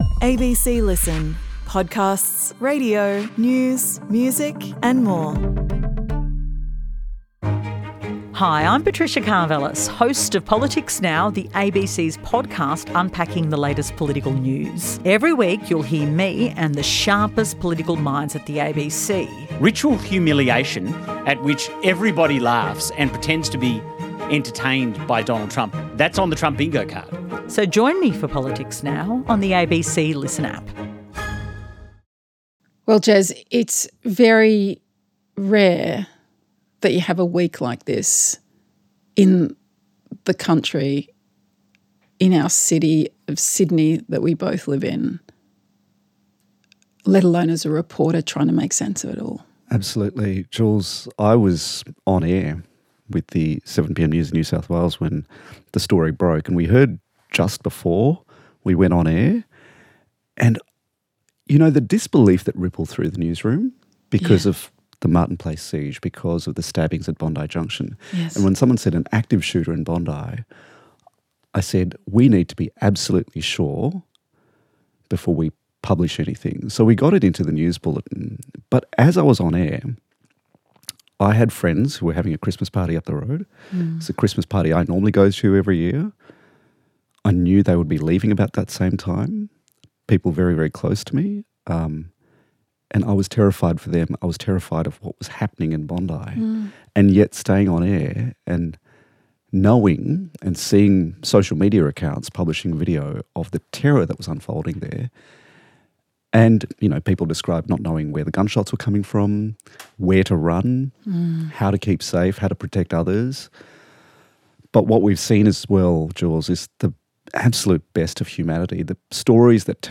The terrorist attack that took place at Bondi Beach at the Jewish festival of Hanukkah on Sunday evening left fifteen dead, 40 injured and the Jewish community fearing for their lives. Jules and Jez talk about gun control, antisemitism and the heroism and kindness of ordinary people.
Julia Baird and Jeremy Fernandez chat about the stories you're obsessed with, the stuff you've missed and the things that matter.